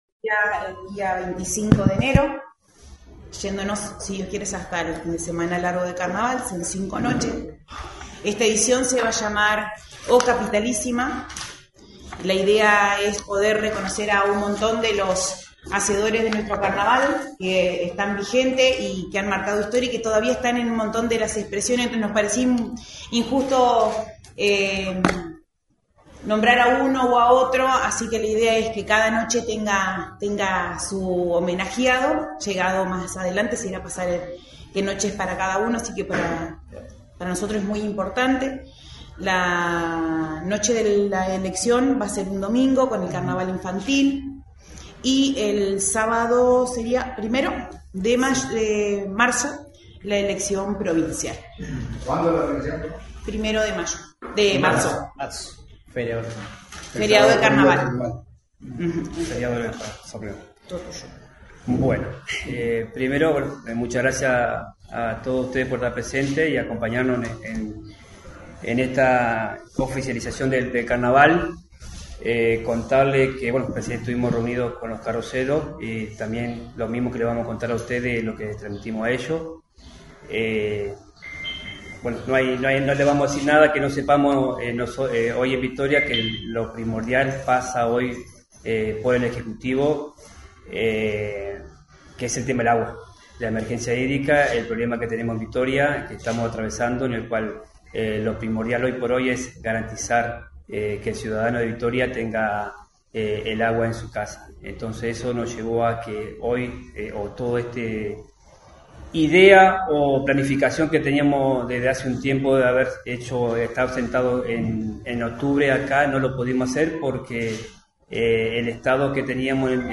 Conferencia-carnaval.mp3